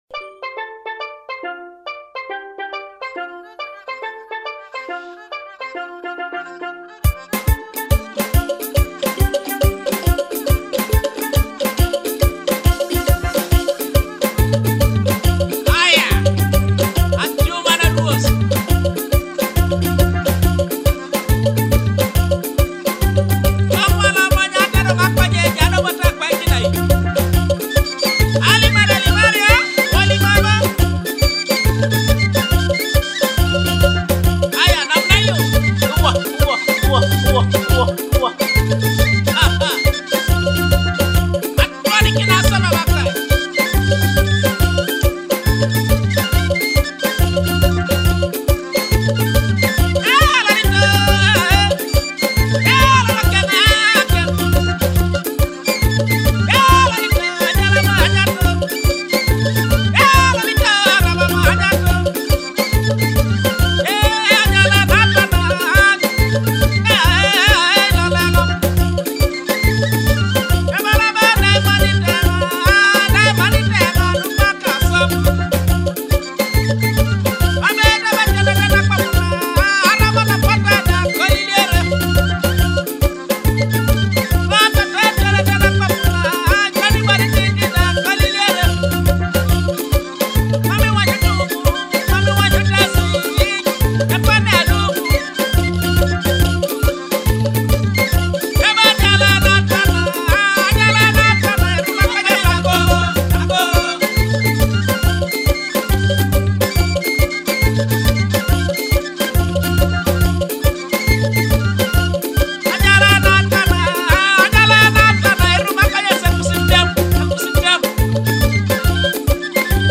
Teso cultural and traditional rhythms in Akogo and Adungu